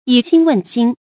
以心問心 注音： ㄧˇ ㄒㄧㄣ ㄨㄣˋ ㄒㄧㄣ 讀音讀法： 意思解釋： 在心中自問自盤算。